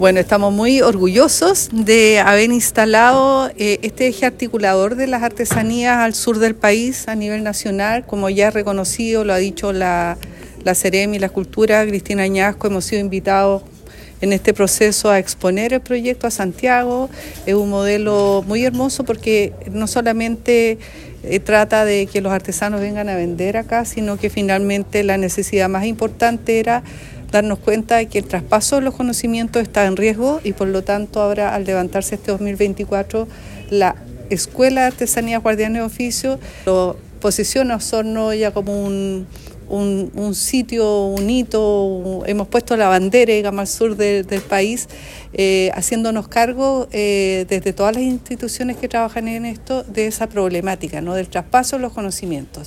Este lunes, en la sala de sesiones del municipio de Osorno, se realizó el lanzamiento oficial de “Guardianes de Oficios”, una iniciativa que tendrá lugar los días 7, 8 y 9 de noviembre en el Centro Cultural de Osorno.